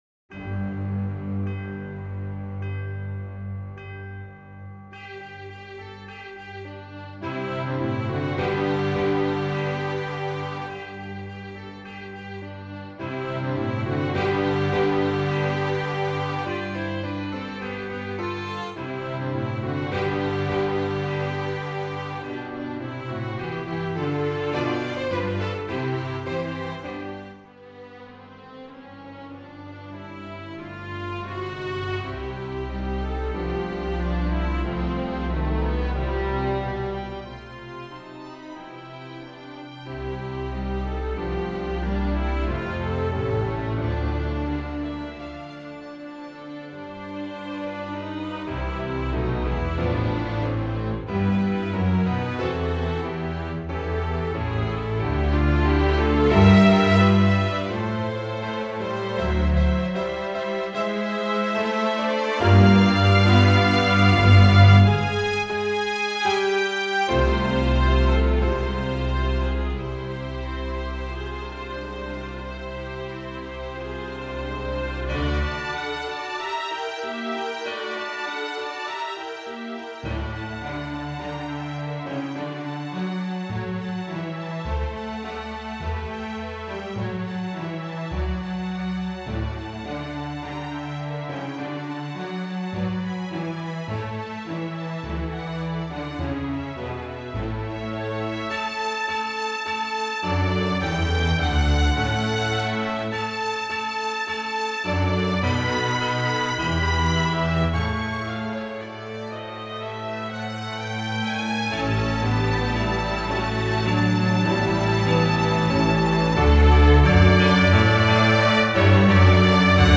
Voicing: String Orchestra